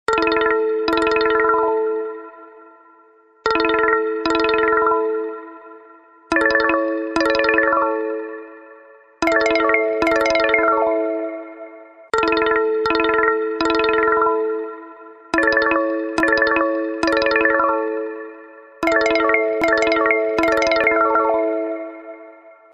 • Качество: 128, Stereo
спокойные
без слов
инструментальные
простые
простенький незатейливый рингтон